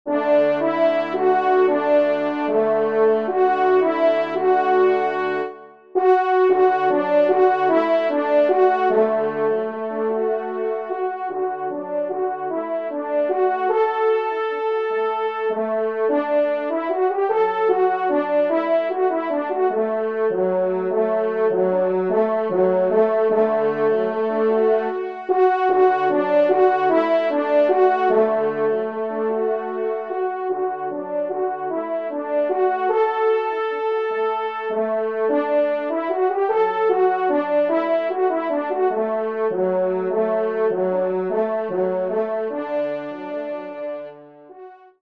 Genre :  Divertissement pour Trompes ou Cors en Ré et Picolo
1e Trompe